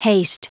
Prononciation du mot haste en anglais (fichier audio)
Prononciation du mot : haste